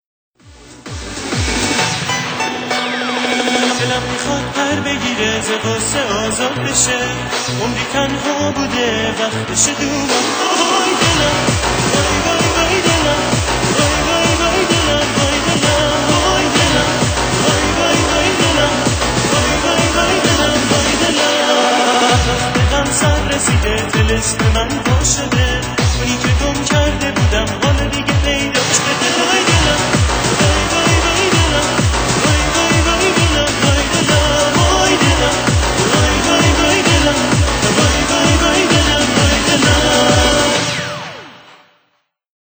استفاده از گیتار راک تو این آهنگش حرف نداره